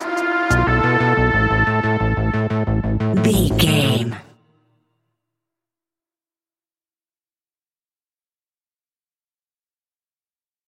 Aeolian/Minor
scary
ominous
dark
eerie
synthesiser
drums
percussion
horror music